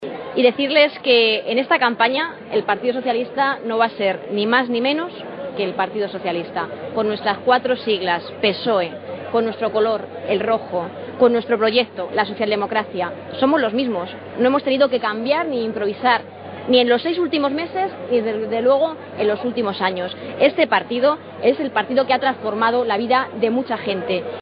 Acto público en Puertollano
Cortes de audio de la rueda de prensa